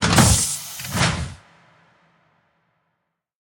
cryo_open.ogg